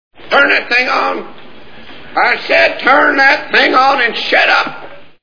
Little Big Man Movie Sound Bites